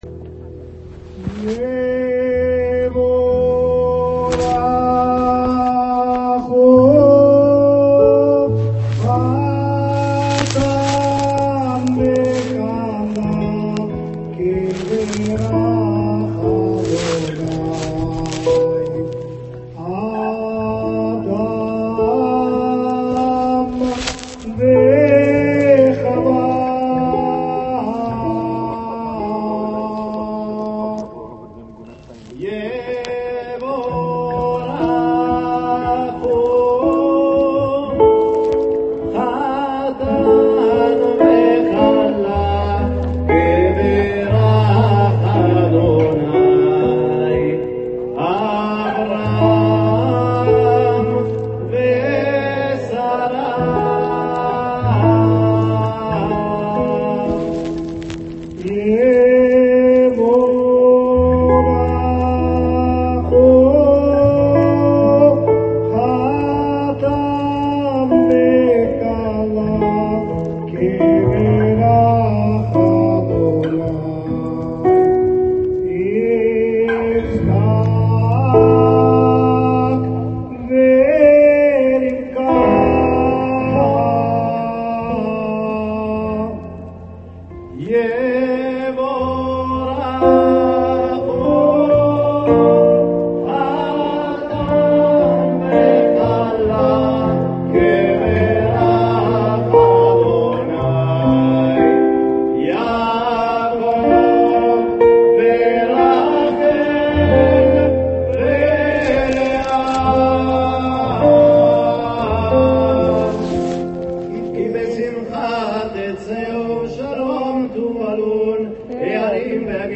dal vivo